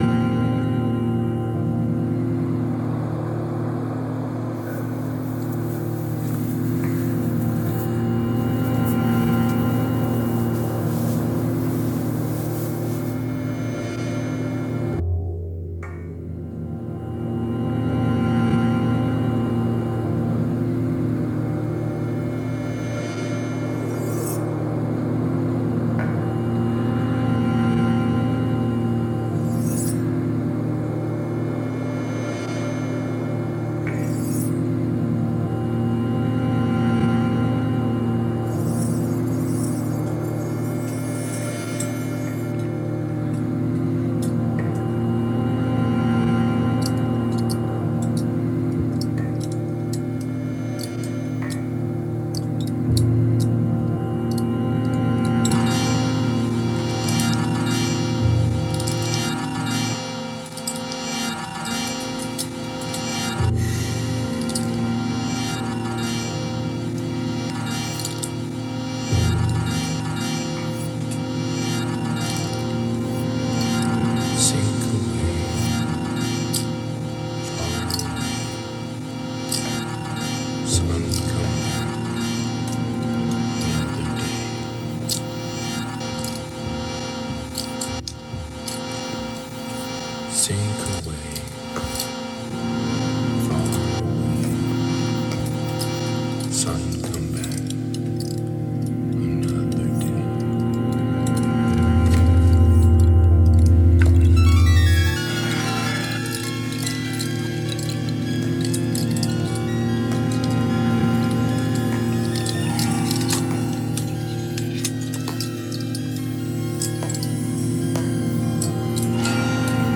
Lots of sample sources. Floor tom, knife, windchime, guitar, etc